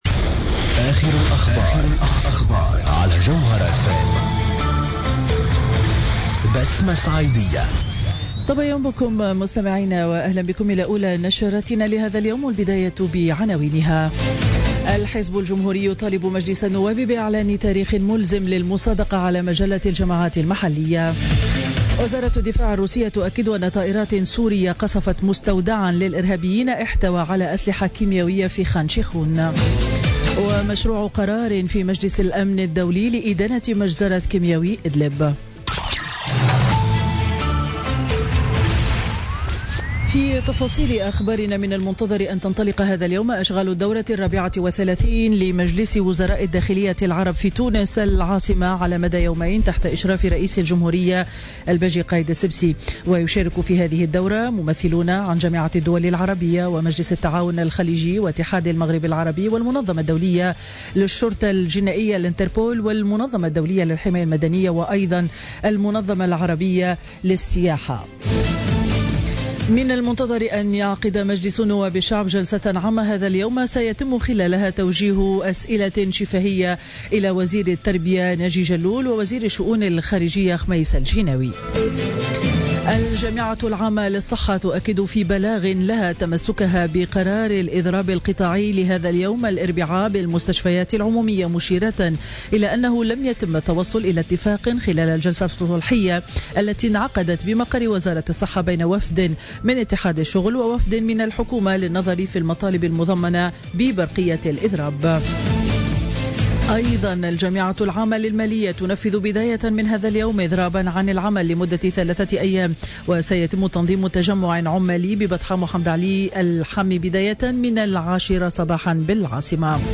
نشرة أخبار السابعة صباحا ليوم الاربعاء 5 أفريل 2017